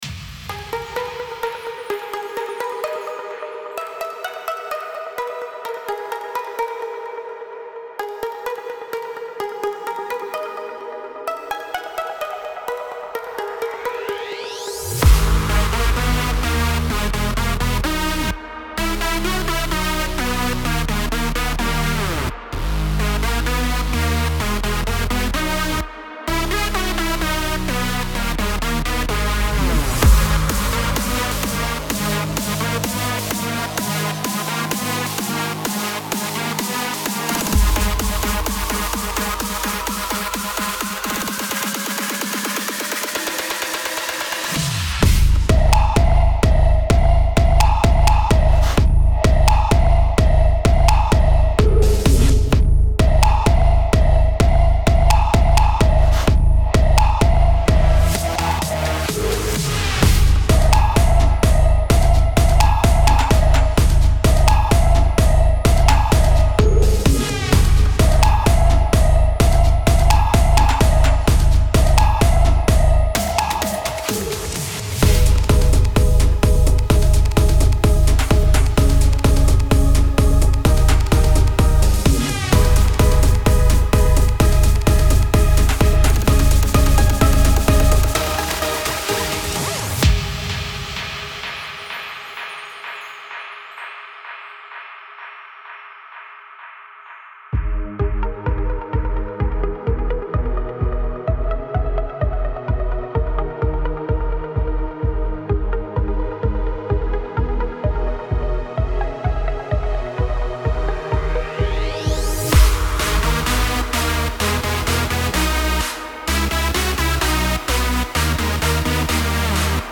سبک پر‌انرژی , رقص , موسیقی بی کلام , ورزشی
موسیقی بی کلام دنس